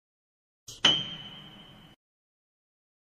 Play, download and share Piano tin original sound button!!!!
piano-tin.mp3